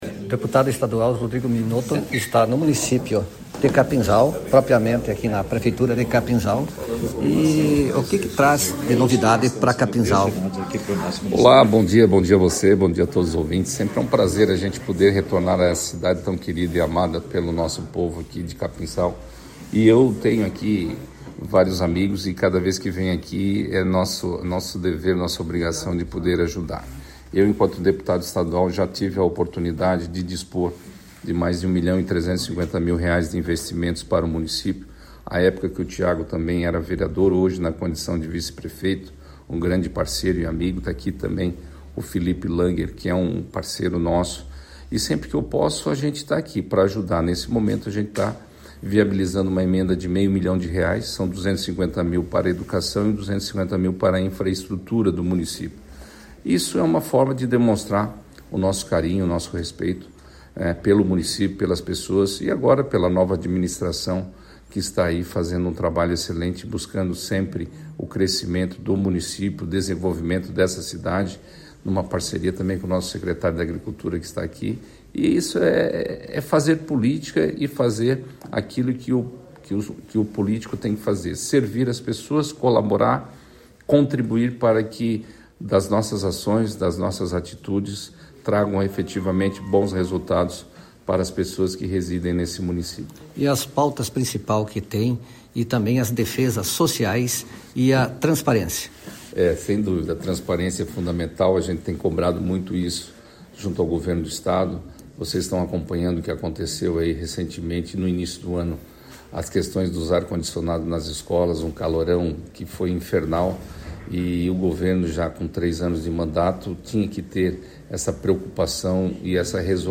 Áudio_com_o_deputado_estadual_Rodrigo_Minotto.mp3